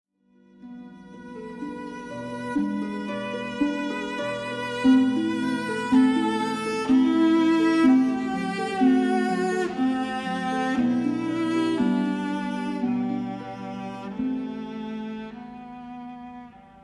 Sa musique, écrite dans la tradition classique française, est élégante et précise dans le détail et la forme!; elle associe le style lyrique de la musique française du XIXe siècle et une qualité plus formelle.Elle a des sonorités chaudes et riches annonce les œuvres de Claude Debussy et de Maurice Ravel.